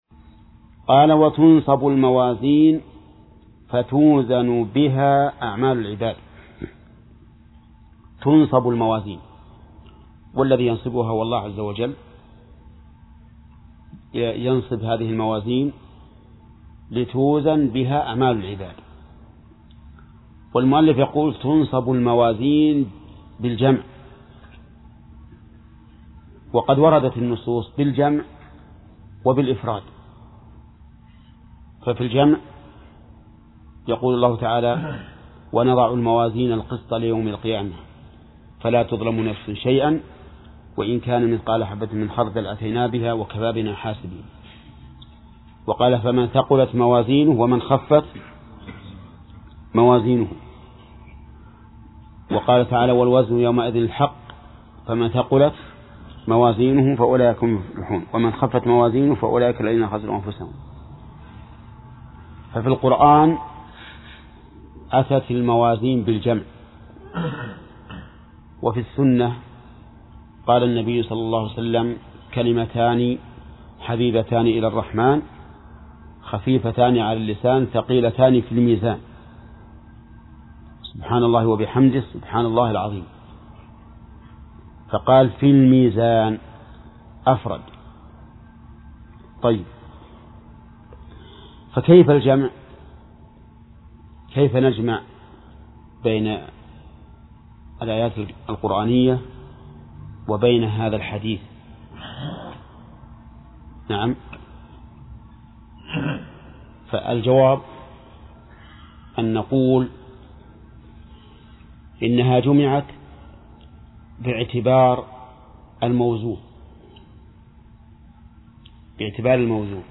درس : 29 : من صفحة: (138)، (قوله: (الأمر الخامس مما يكون يوم القيامة).إلى صفحة: (160)، (قوله: الأمر التاسع مما يكون يوم القيامة ....).